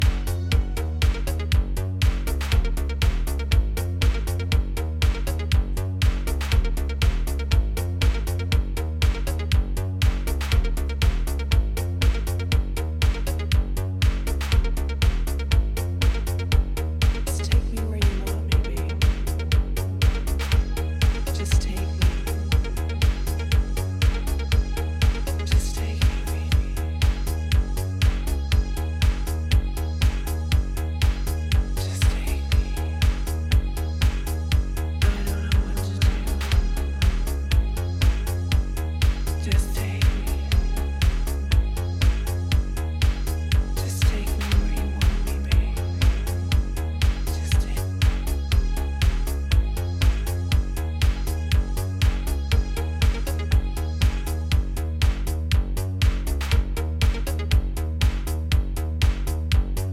インストダブmix